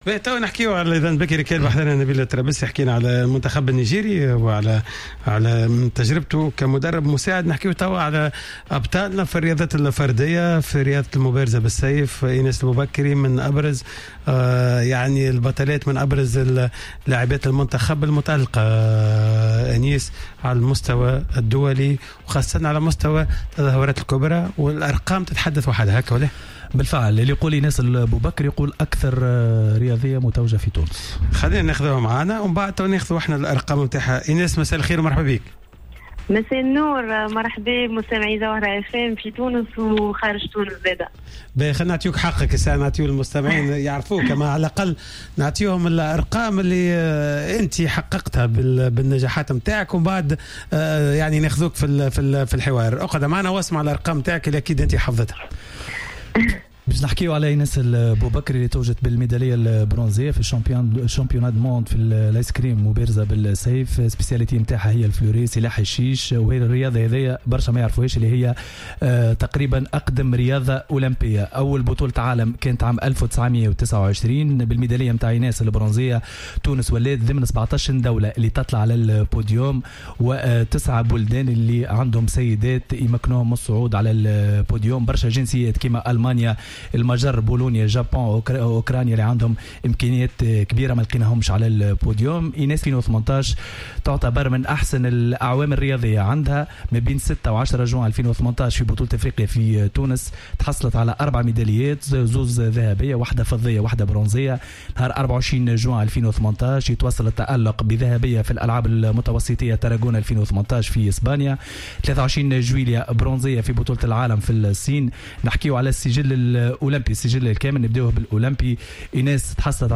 تحدثت البطلة التونسية في رياضة المبارزة إيناس البوبكري في مداخلة في حصة راديو سبور اليوم الأحد 05 أوت 2018 عن عدد من الصعوبات و العراقيل التي واجهتها خلال التحضيرات التي تسبق مشاركاتها في عدد من البطولات العالمية.